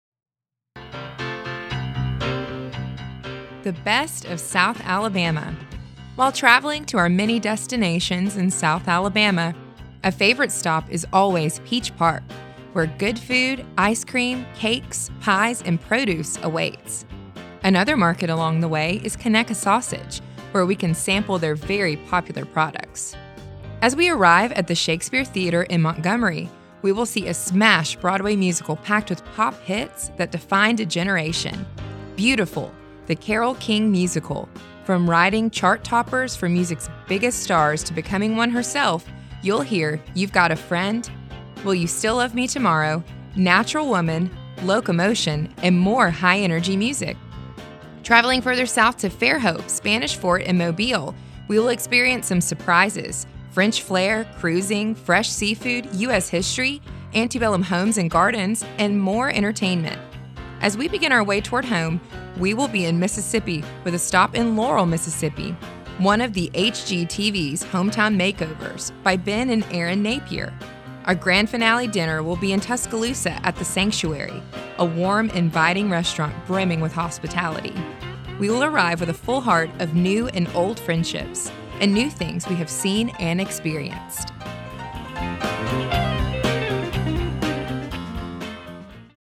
5.-Aug-13-Best-of-South-feat.-I-feel-the-Earth.mp3